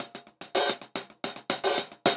Index of /musicradar/ultimate-hihat-samples/110bpm
UHH_AcoustiHatC_110-05.wav